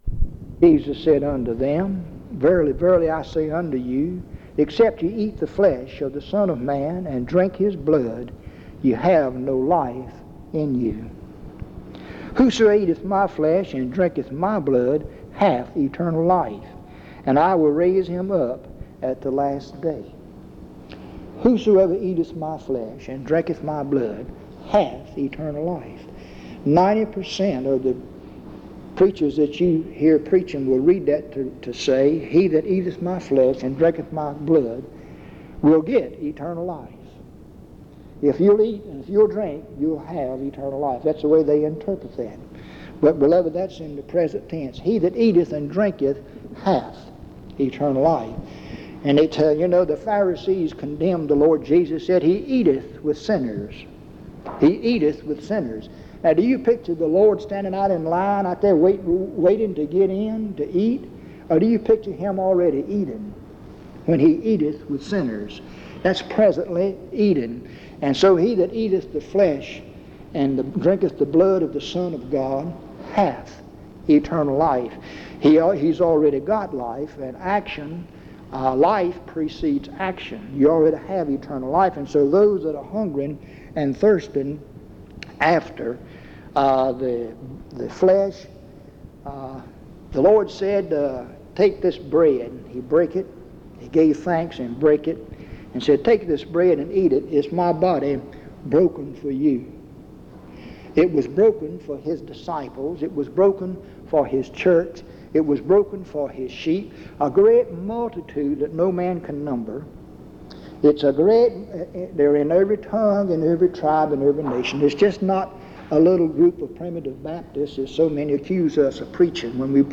Dans Collection: Reidsville/Lindsey Street Primitive Baptist Church audio recordings La vignette Titre Date de téléchargement Visibilité actes PBHLA-ACC.001_030-B-01.wav 2026-02-12 Télécharger PBHLA-ACC.001_030-A-01.wav 2026-02-12 Télécharger